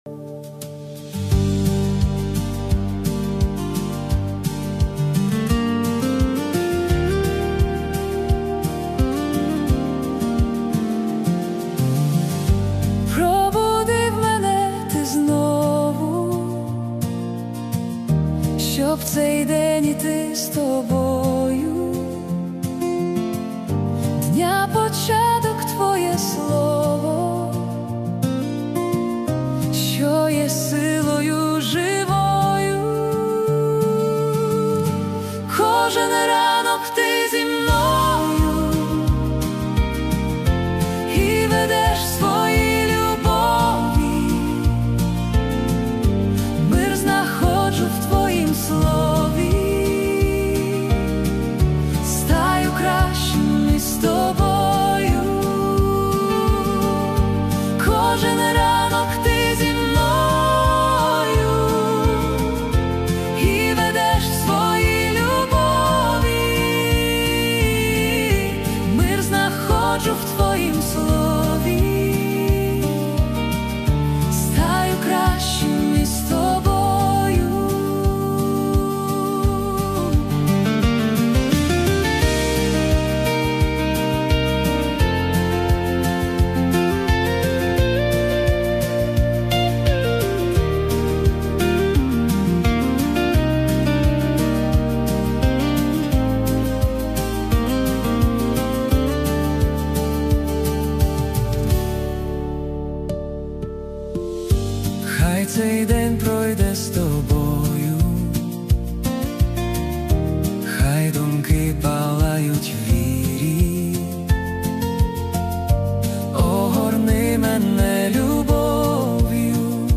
песня ai
5 просмотров 13 прослушиваний 0 скачиваний BPM: 78 4/4